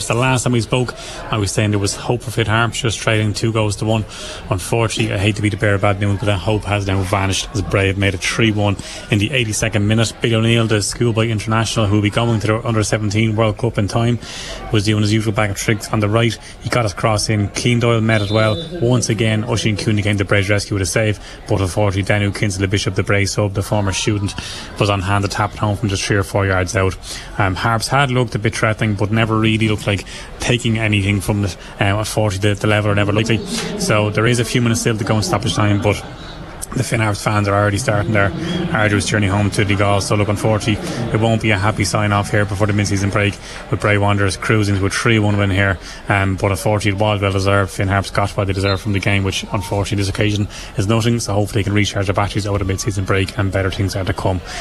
was live as full time approached for Highland Radio Sport…